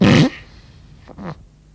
文件夹中的屁 " 屁 01
描述：从freesound上下载CC0，切片，重采样到44khZ，16位，单声道，文件中没有大块信息。准备使用！在1个文件夹中有47个屁;）
Tag: 喜剧 放屁 效果 SFX soundfx 声音